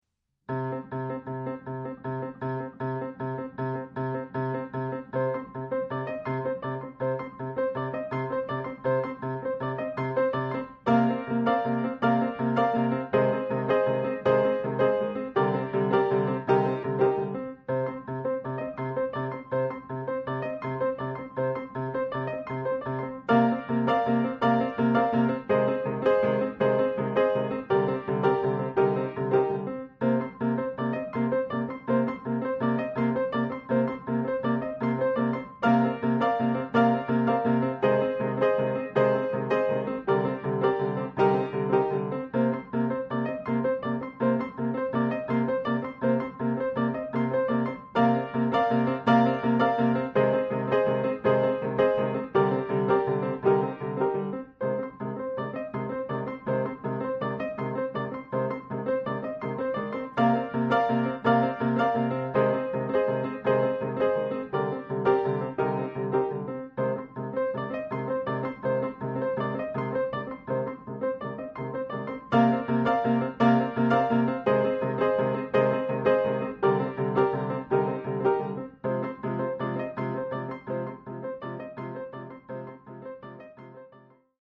in fa minore